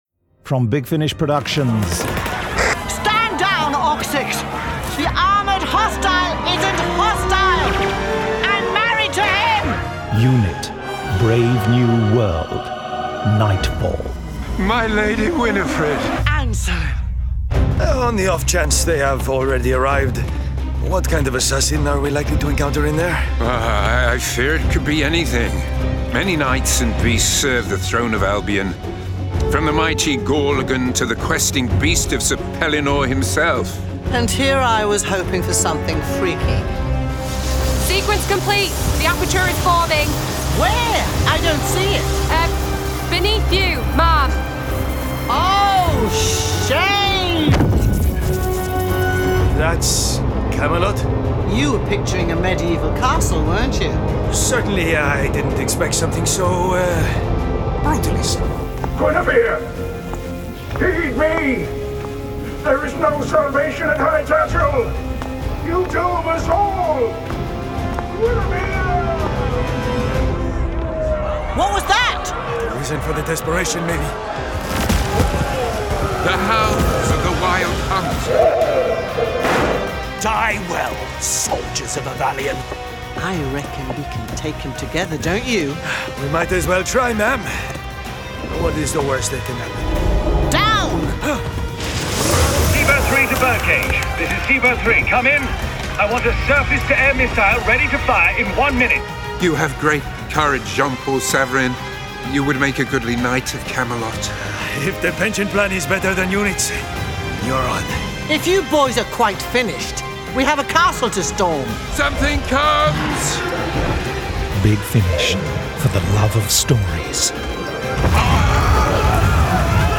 Award-winning, full-cast original audio dramas from the worlds of Doctor Who, Torchwood, Blake's 7, Class, Dark Shadows, Avengers, Omega Factor, Star Cops, Sherlock Holmes, Dorian Gray, Pathfinder Legends, Prisoner, Adam Adamant Lives, Space 1999, Timeslip, Terrahawks, Space Precinct, Thunderbirds, Stingray, Robin Hood, Dark Season, UFO, Stargate, V UK, Time Tunnel, Sky, Zygon Century, Planet Krynoid, Turpin, Young Bond